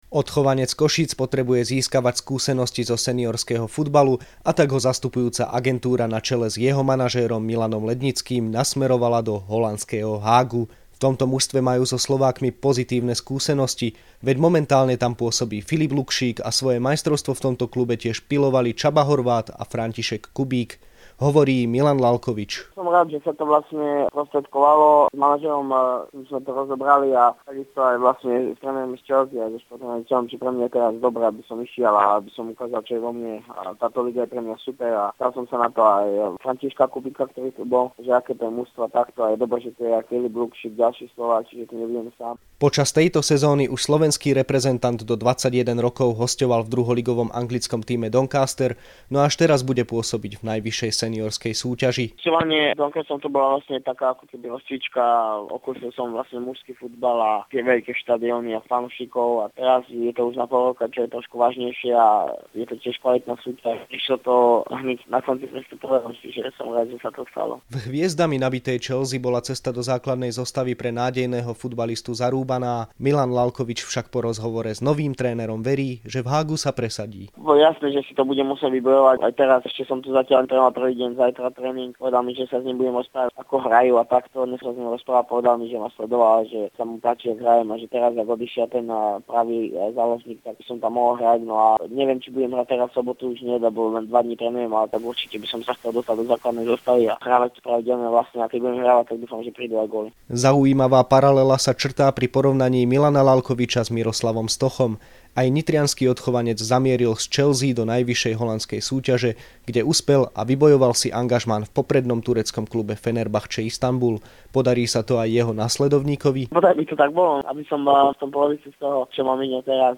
rozhovor
v rozhlasovom vysielaní RTVS